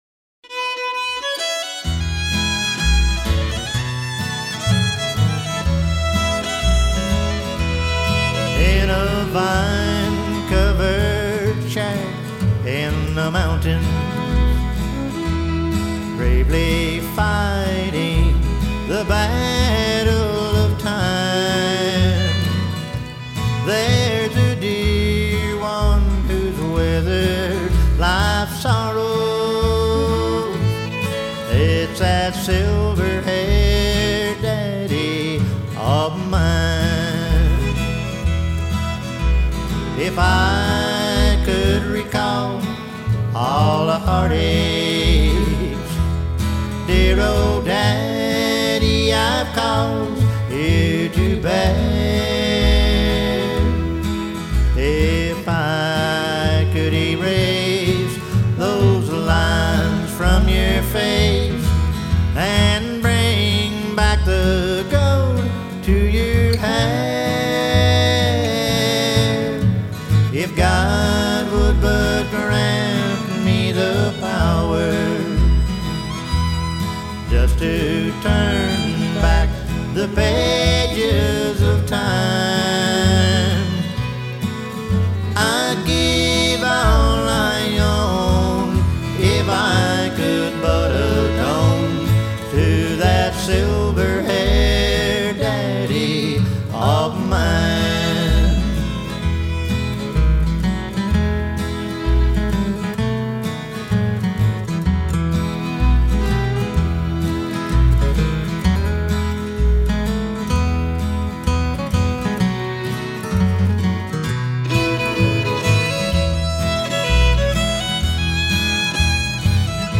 Missouri-area bluegrass artist